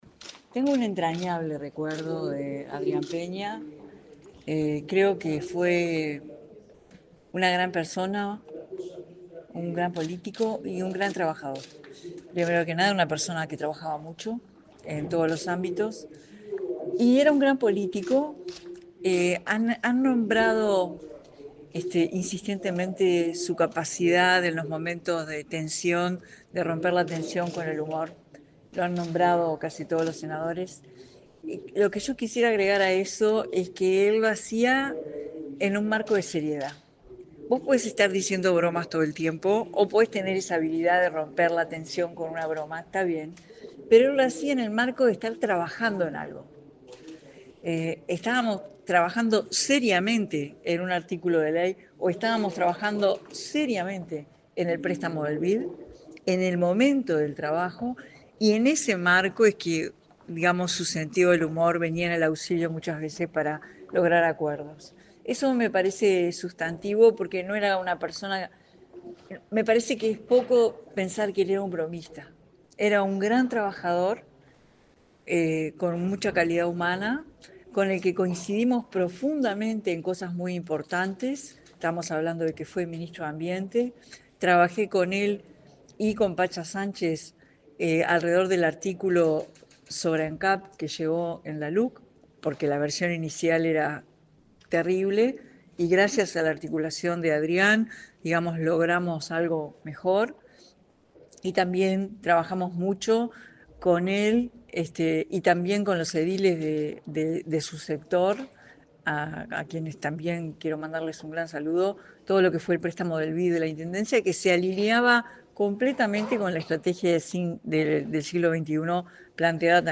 Declaraciones de la presidenta de la República en ejercicio, Carolina Cosse
En ese contexto, dialogó con la prensa acerca de la figura de Peña y subrayó que fue un gran político y trabajador.